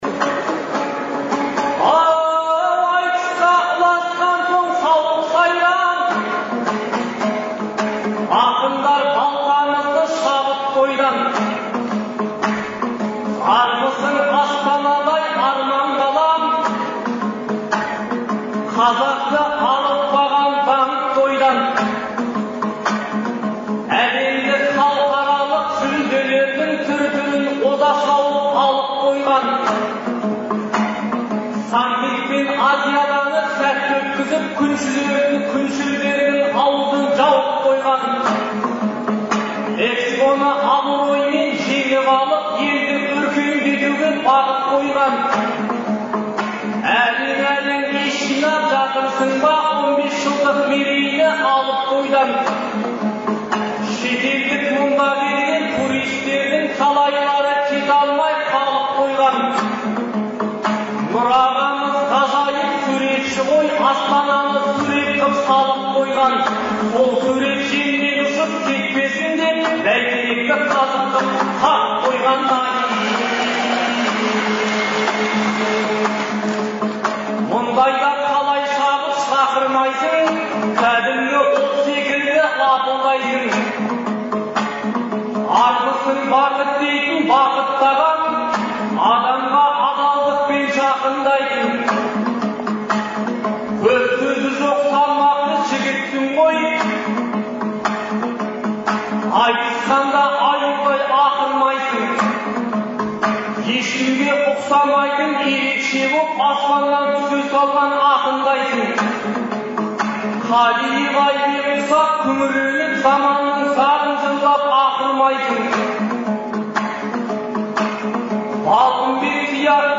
Шілденің 8-9-ы күндері Астанадағы «Қазақстан» орталық концерт залында «Ел, Елбасы, Астана» деген атпен ақындар айтысы өтті. Айтыстың алғашқы күні 10 жұп (20 ақын) сөз сайыстырды.